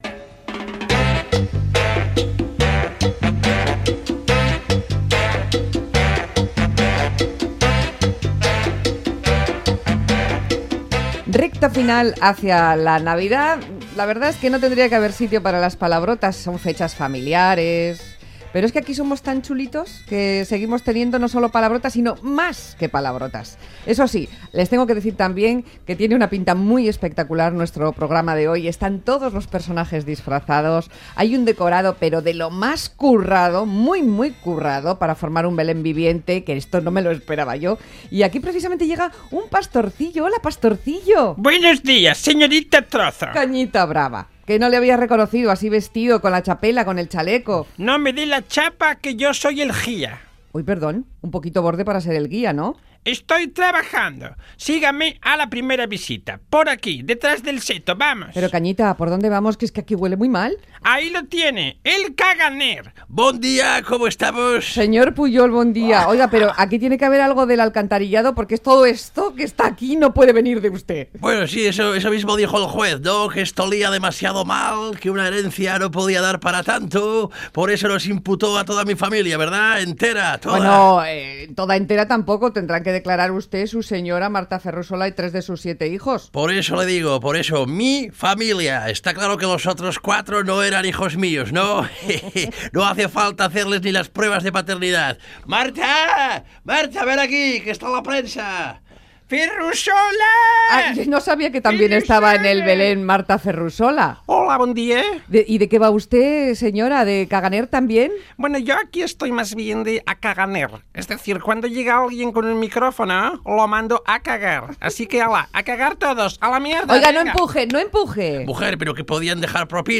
Imitaciones Radio Euskadi Más que Palabras